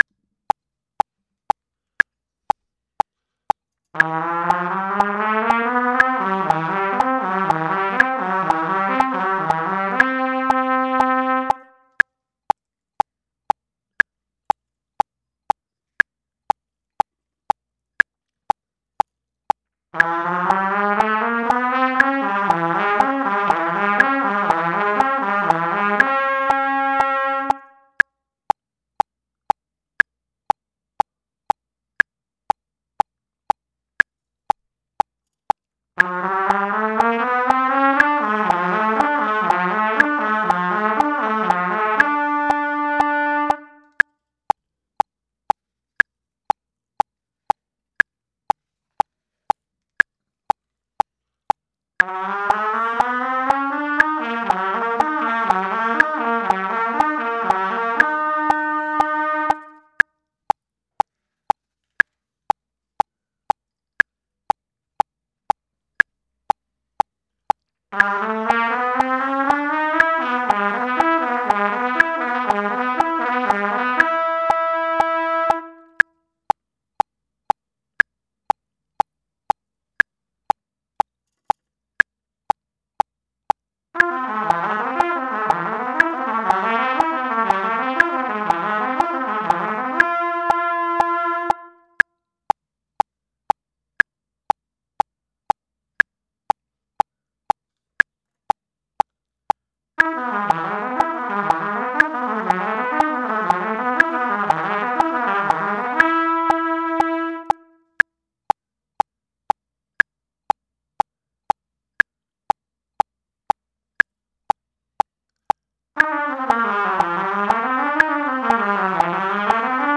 Finger Exercises
Finger-Exercise-120.mp3